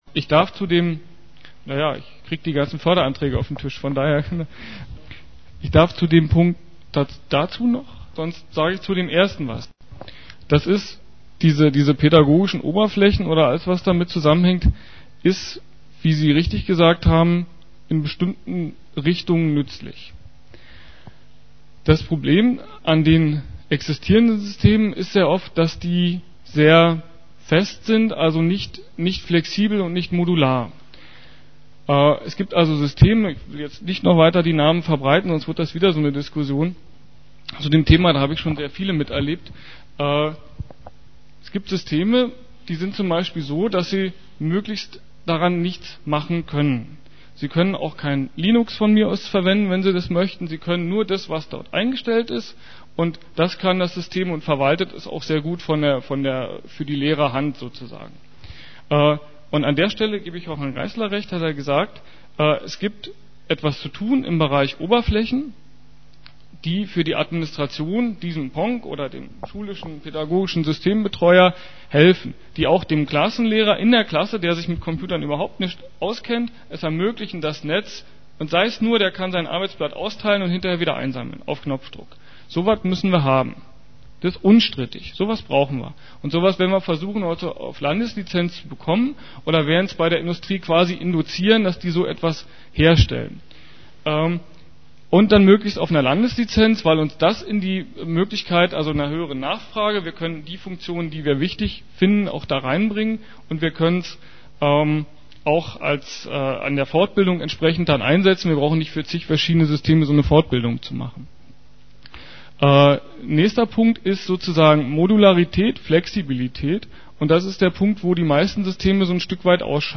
diskussion-frage2-2.mp3